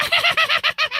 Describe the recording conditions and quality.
wicked-quest - Halloween themed side scroler audio game